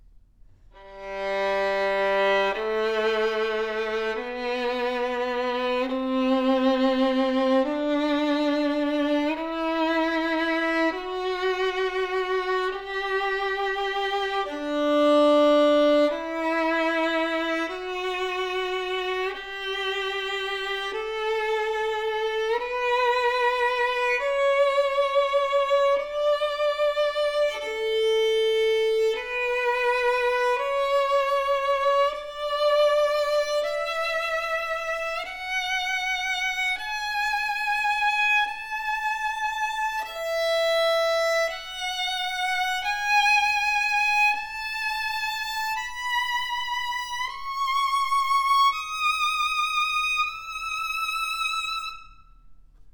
Audio Clip ( Scale ):
Full, deep open sounding with thick texture on G string.
Mellow, sweet and resonant voice in the mid register, rich character that sings in a rounded tone. Brilliant and penetrating tone on E string, wonderful overtone that projects with great sustain.